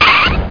screech.mp3